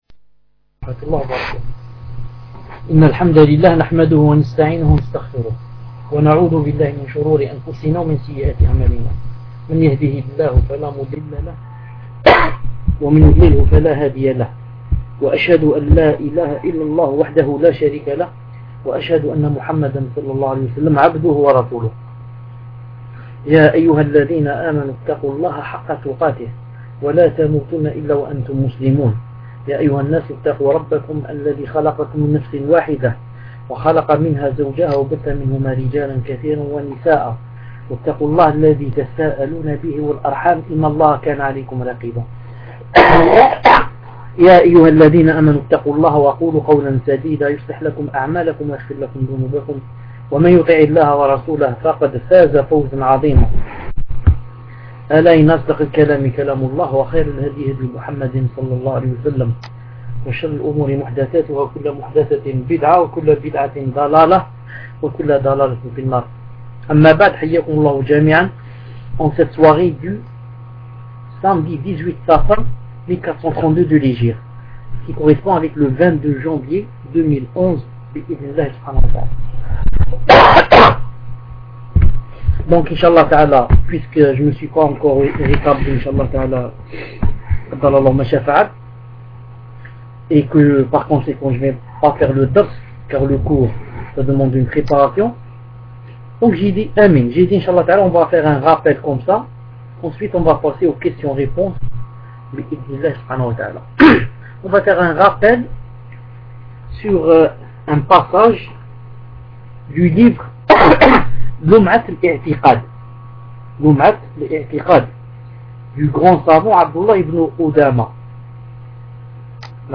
Samedi 18 Safar 1432 | 22 Janvier 2011 | 1er cours - Durée : 2h 29 min